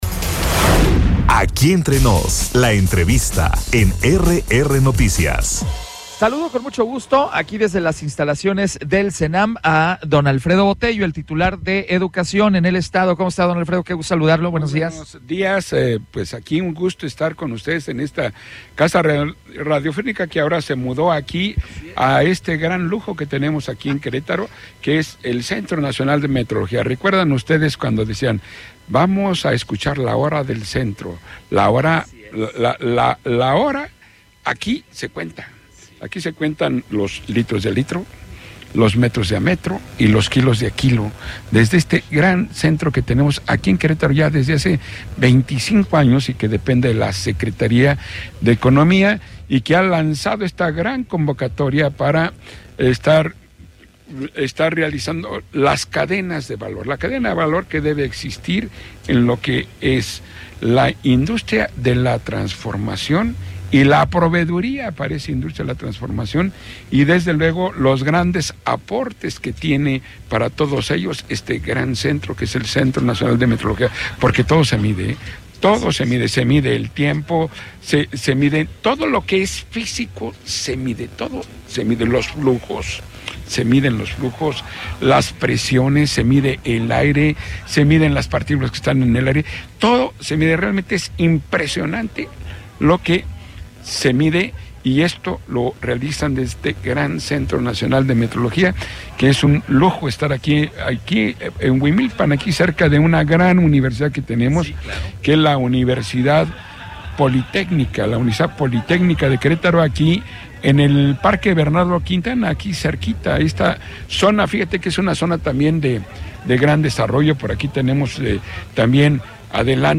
ENTREVISTA-ALFREDO-BOTELLO-MONTES-SECRETARIO-DE-EDUCACION.mp3